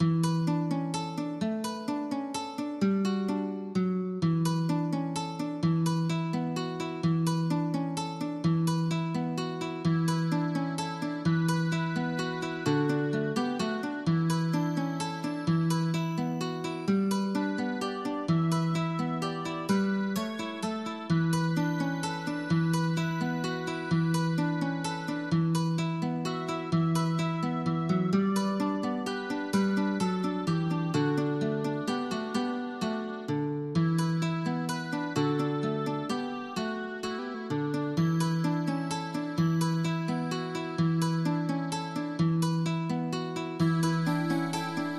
MIDI · Karaoke